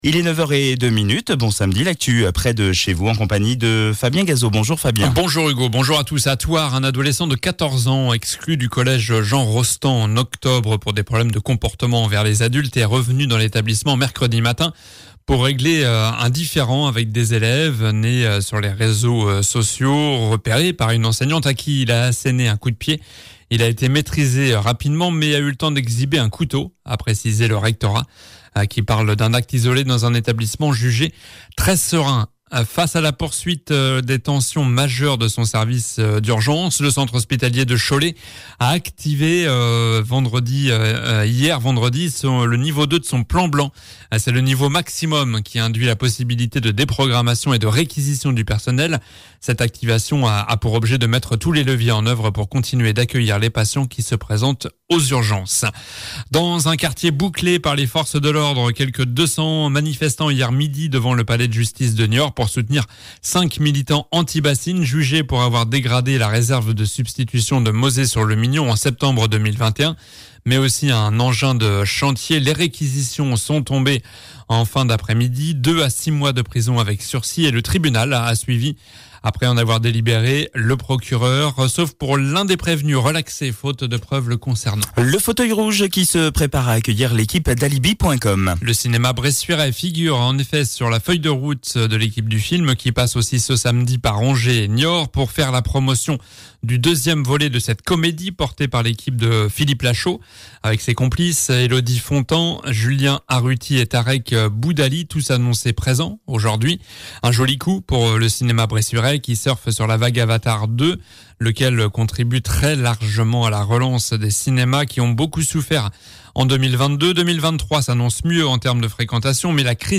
Journal du samedi 07 janvier (matin)